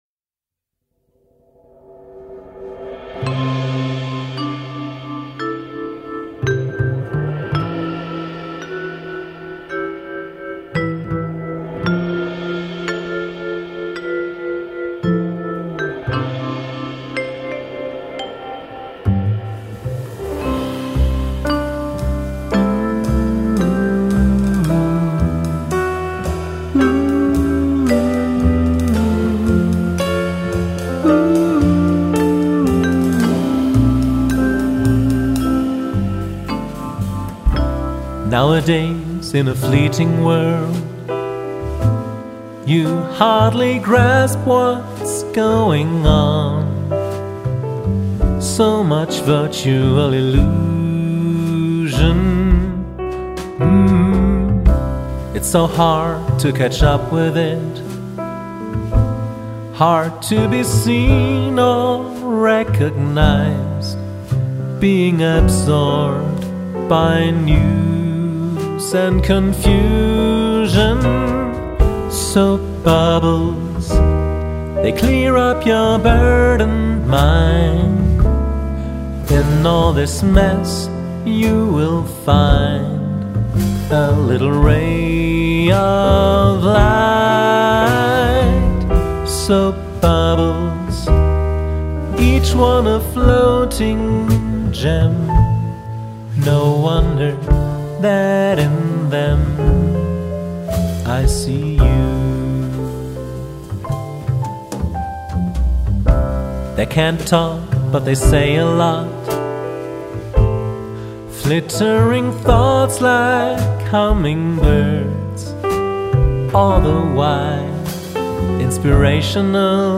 Solo
Genre: Songkabarett, JazzPopSingerSongwriter, Songpoesie
Sänger/in
• Klavier